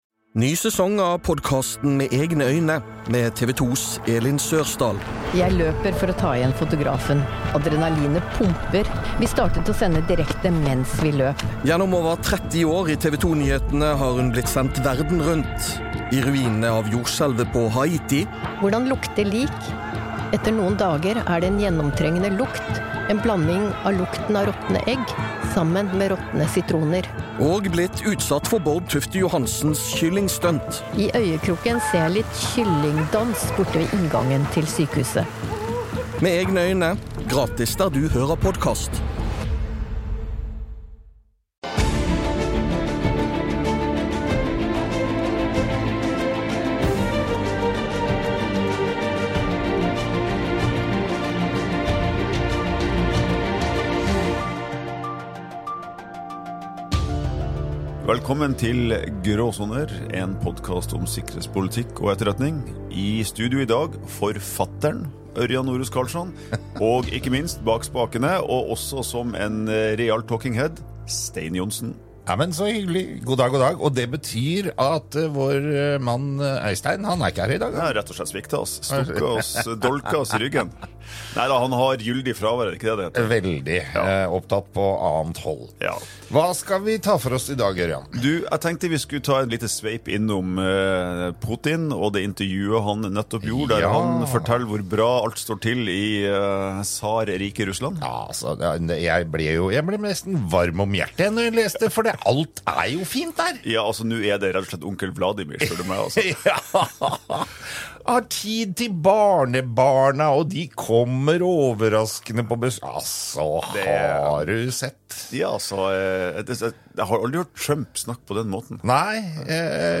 En samtale